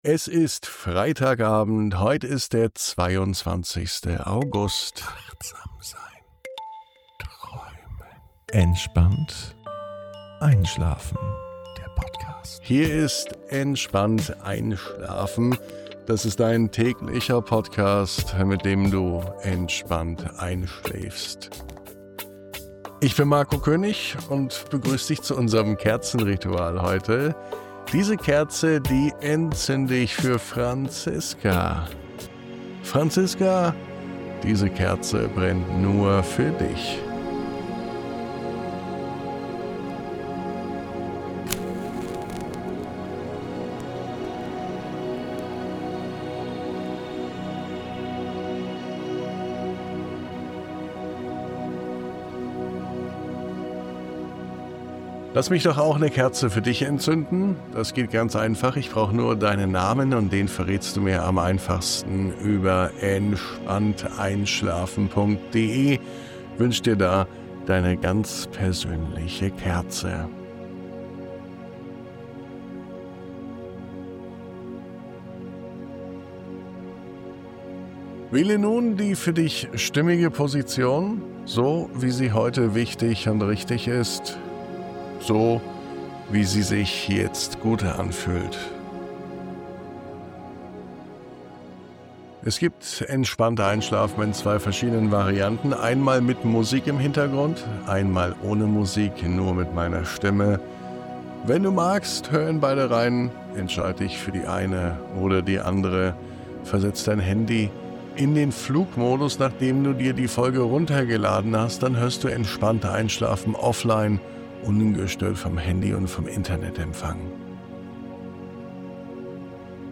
Diese Traumreise lädt dich ein, dich tragen zu lassen.